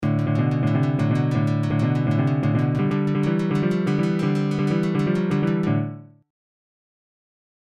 > Blues Licks
Blues+Licks.mp3